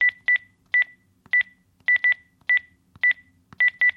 Звуки набора телефона
В коллекции представлены как современные тональные сигналы мобильных устройств, так и характерный щелкающий звук старого дискового телефона.